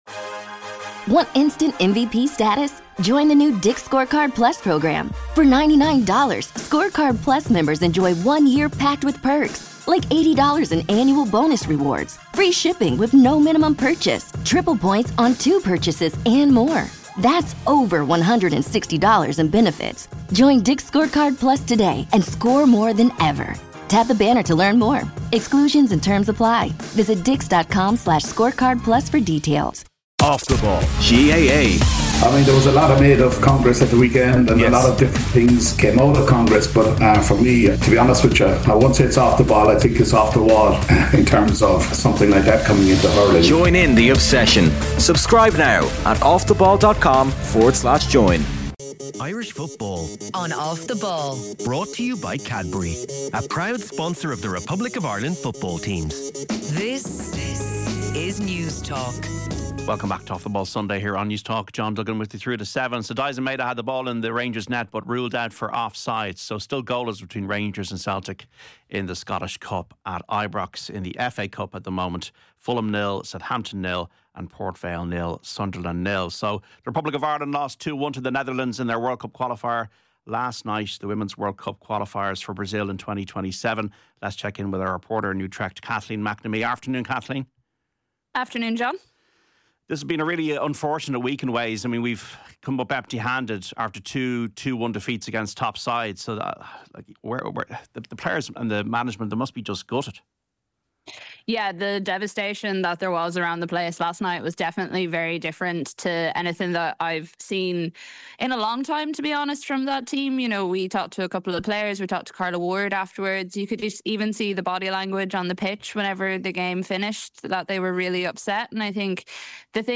Troy Parrott’s red-hot form at AZ Alkmaar continues, with Kevin Kilbane joining the Football Show to discuss the Irish striker’s impressive rise.